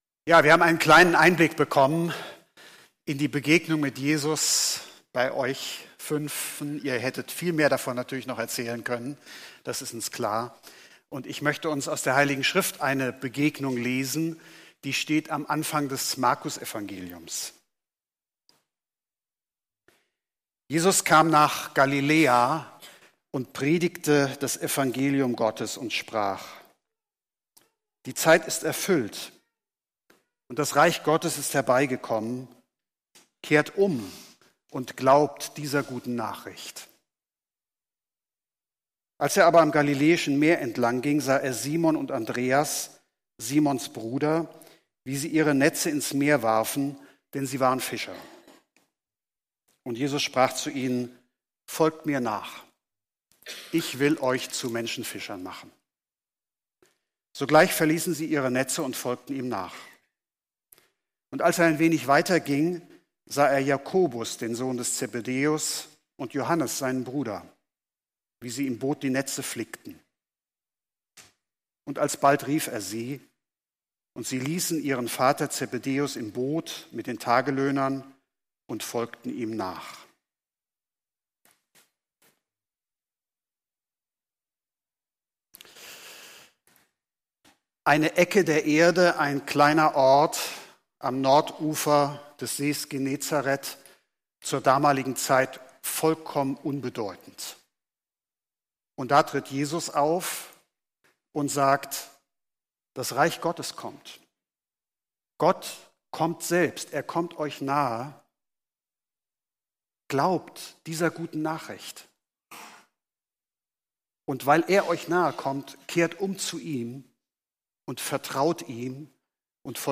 Taufgottesdienst